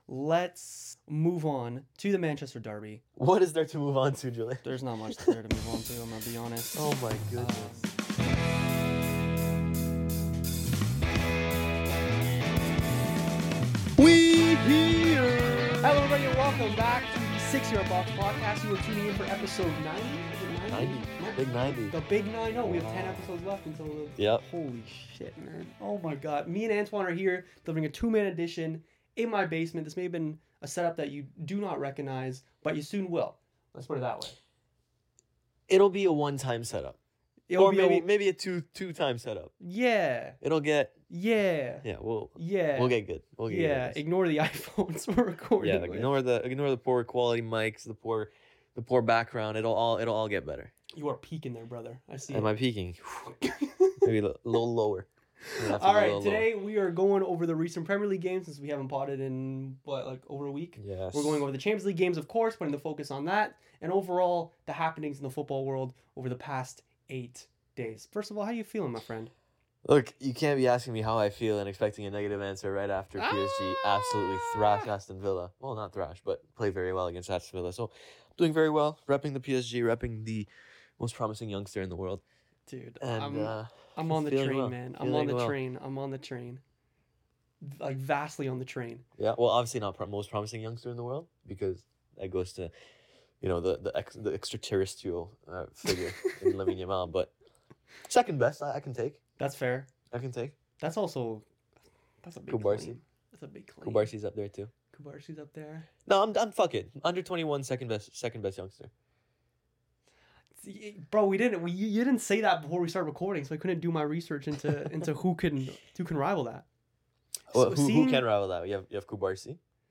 Three man pods are back!